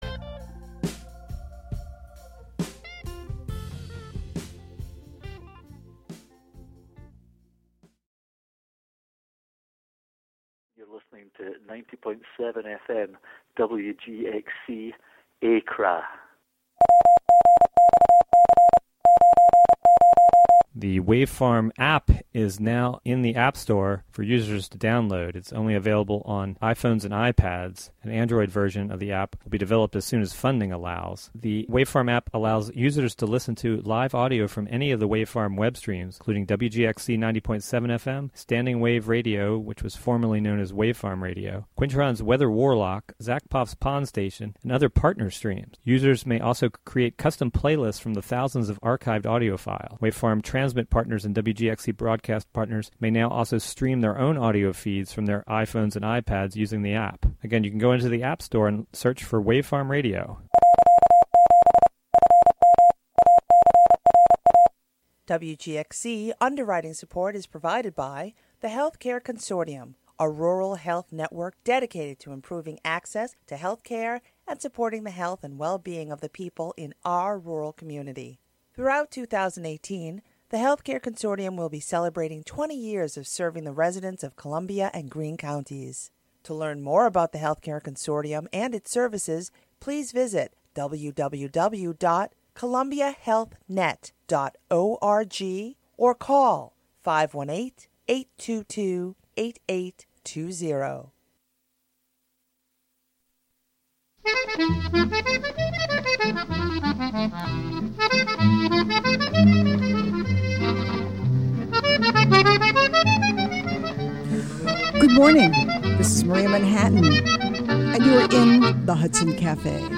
Comedy is the theme for this broadcast, featuring some classics from Bob and Ray and Nichols and May, along with songs with a light-hearted or humorous approach – some from Broadway shows, others from the jazz world. An upbeat music show featuring the American songbook, as interpreted by contemporary artists as well as the jazz greats in a diverse range of genres. Broadcast live from the Hudson studio.